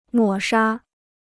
抹杀 (mǒ shā) Free Chinese Dictionary